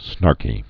(snärkē)